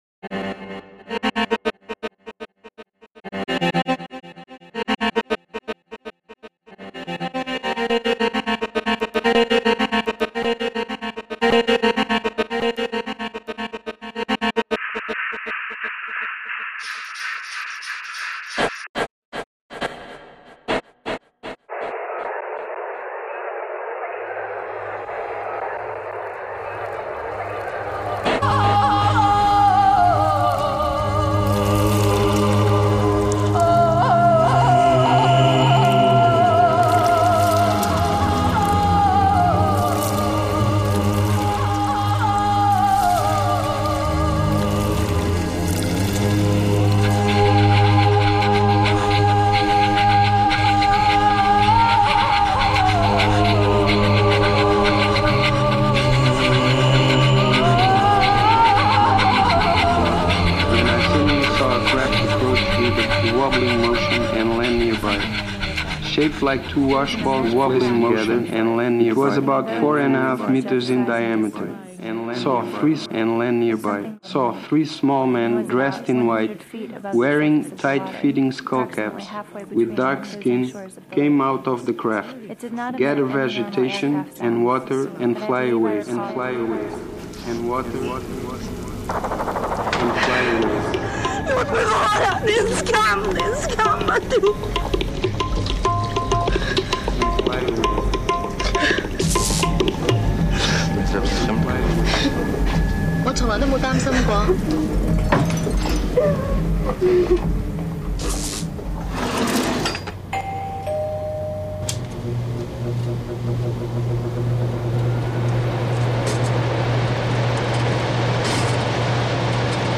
Mp3 with an attempt of aural review of the CD included in this publication. A further note: the CD I got has a big crack on its recorded surface; skips are clearly audible on the first 4 tracks; I didn’t attempt to restore all the audio files, leaving the skips as traces of the many travels the book-plus-CD had made before reaching my mail box.